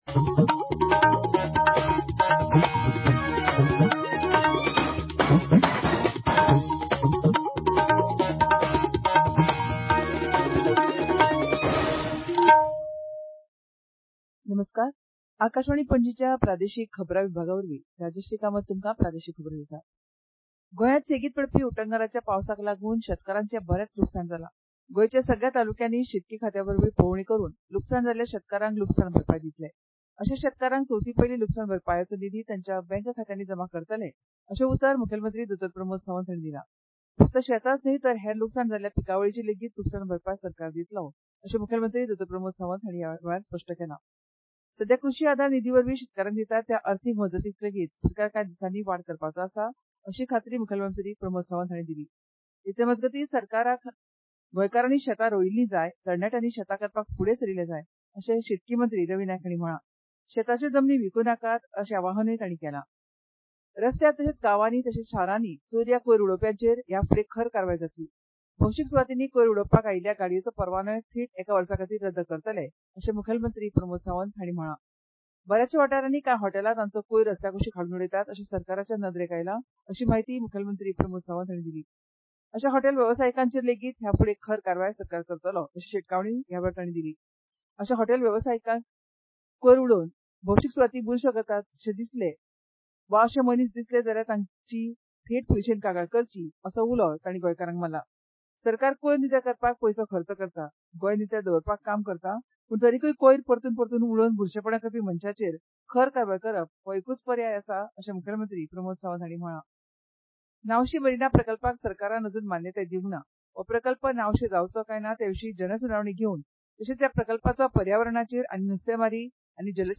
Transcript summary Play Audio Morning News